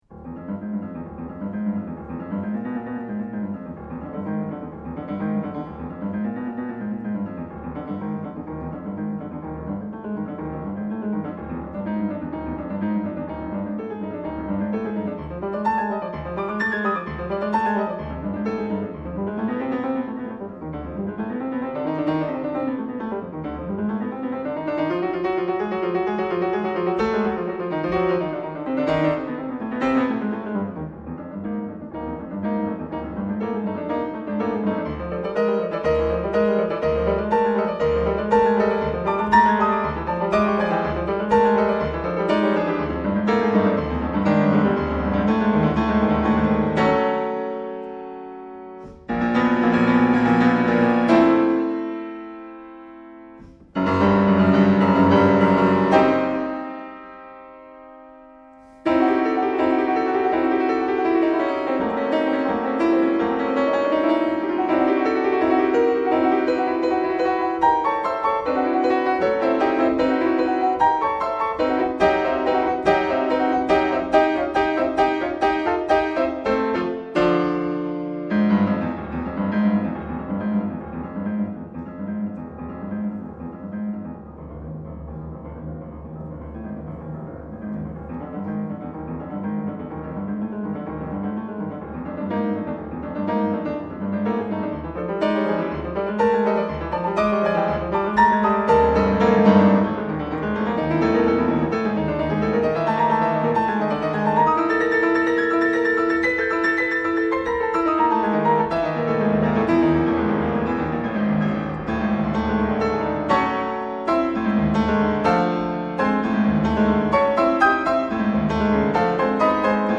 Half note = 124?
D Locrian, if I got it right.